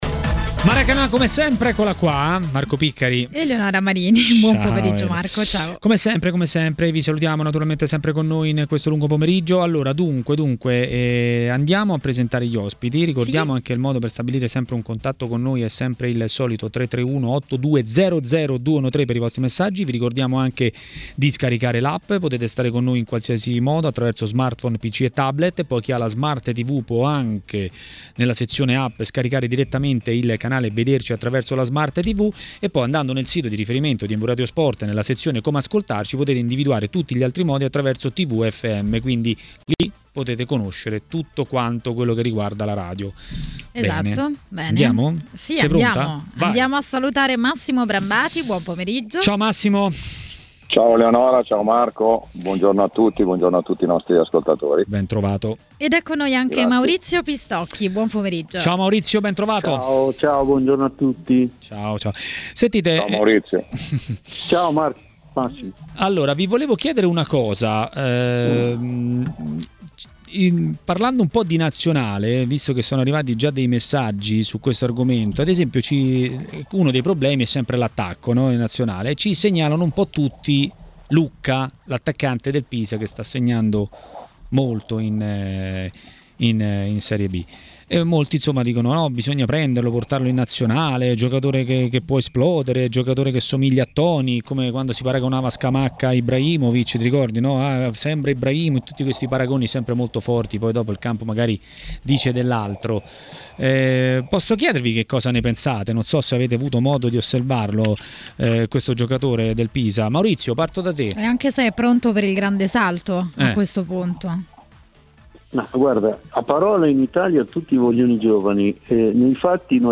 A intervenire a TMW Radio, durante Maracanà